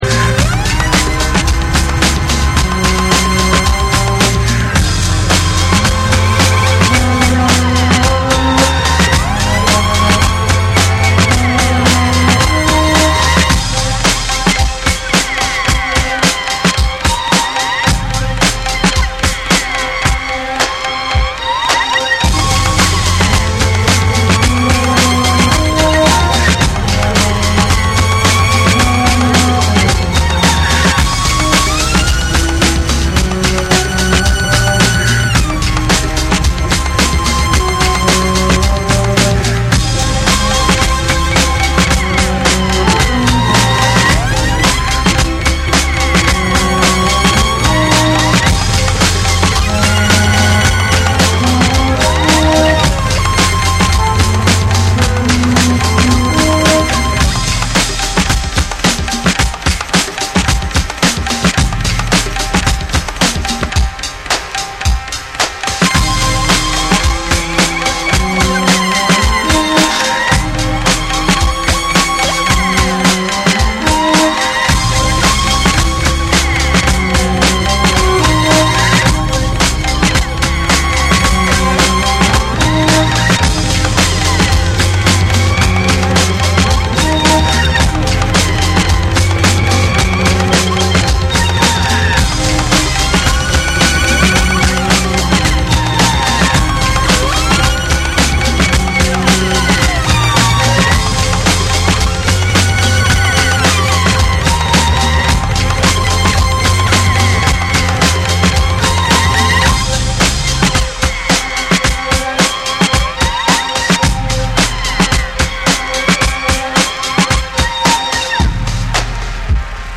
メリハリのある重心低めのブレイクビーツにサイケデリックな演奏が交わる
歪んだベースとノイジーなギター、電子音が交錯する、アグレッシヴでインダストリアルなエレクトロ・ロック
NEW WAVE & ROCK